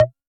RDM_TapeA_SY1-Perc04.wav